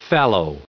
added pronounciation and merriam webster audio
334_fallow.ogg